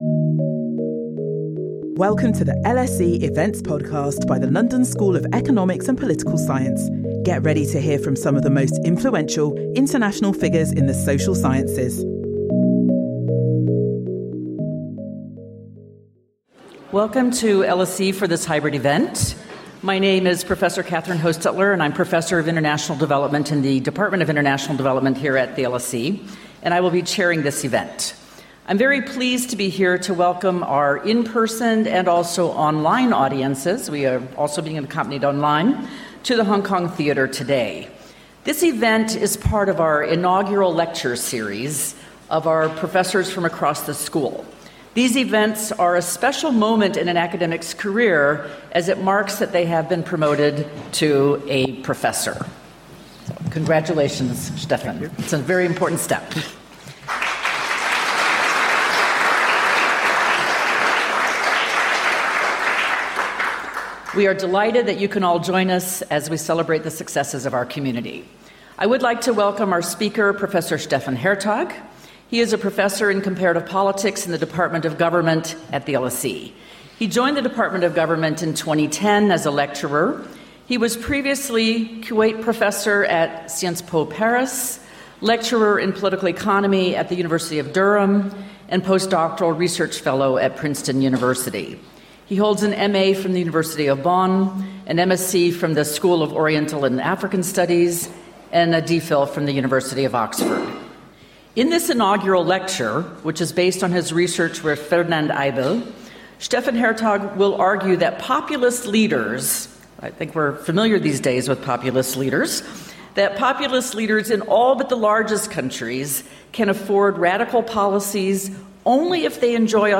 In his inaugural lecture